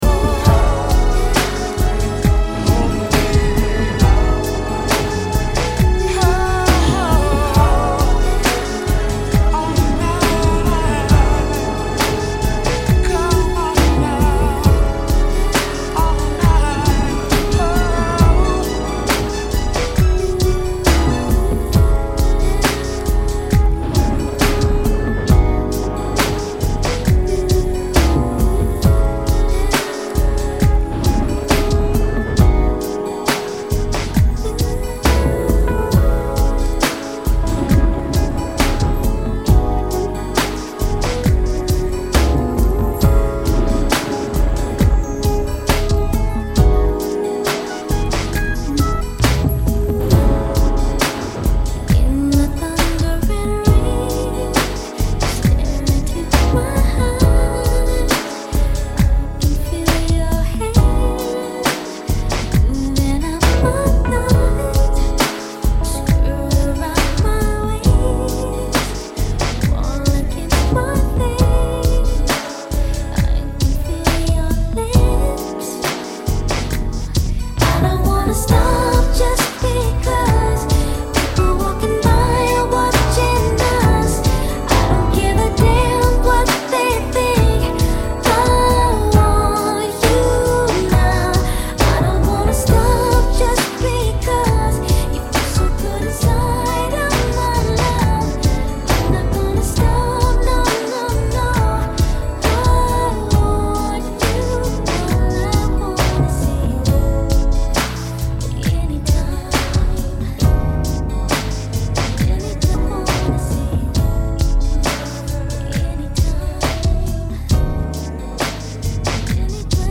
RnB